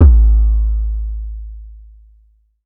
CDK BASSY Kick.wav